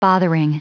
Prononciation du mot bothering en anglais (fichier audio)
Prononciation du mot : bothering